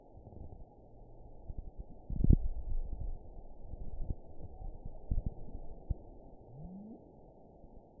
event 915693 date 12/10/22 time 12:06:47 GMT (3 years ago) score 6.05 location TSS-AB05 detected by nrw target species NRW annotations +NRW Spectrogram: Frequency (kHz) vs. Time (s) audio not available .wav